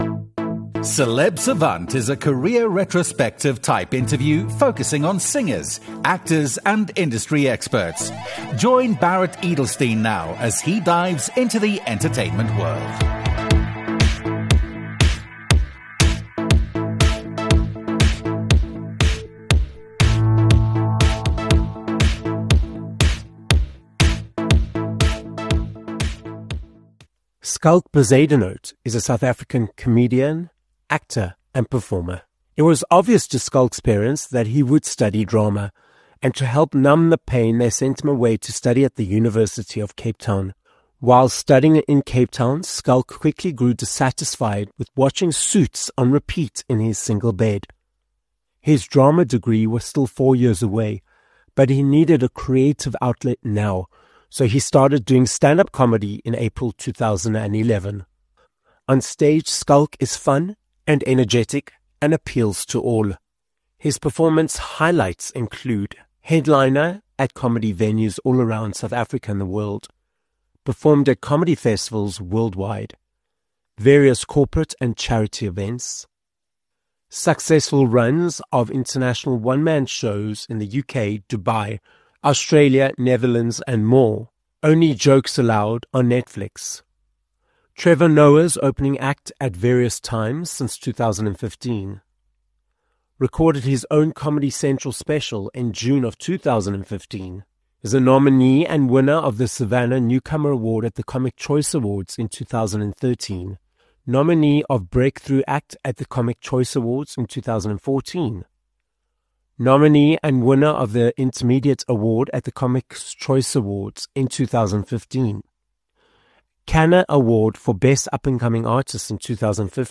Schalk Bezuidenhout - a South African stand-up comedian, actor, and Comics Choice Awards winner - joins us on this episode of Celeb Savant. He explains how his initial goal was to become an actor, that he discovered stand-up at a later stage, how experience has allowed him to become an award-winning performer… and he talks about his latest project - the Showmax original film, ‘Soos Pynappel Op Pizza’.